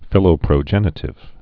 (fĭlō-prō-jĕnĭ-tĭv)